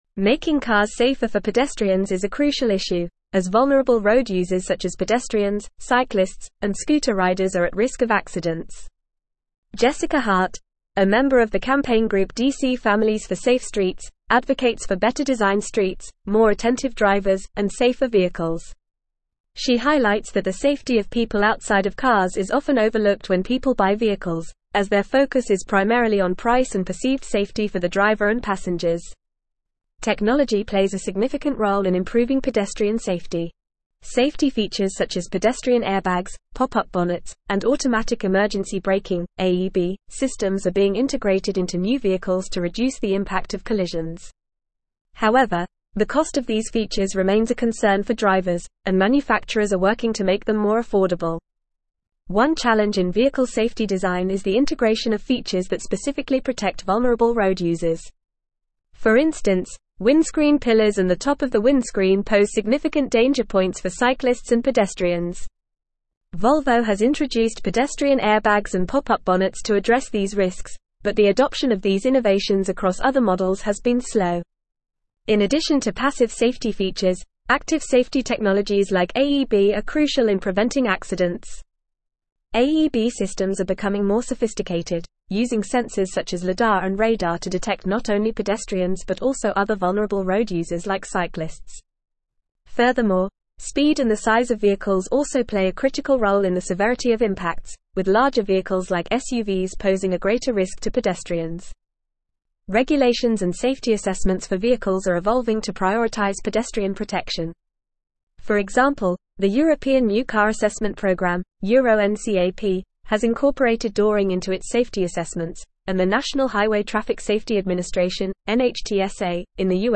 Fast
English-Newsroom-Advanced-FAST-Reading-Advocating-for-Safer-Streets-Protecting-Vulnerable-Road-Users.mp3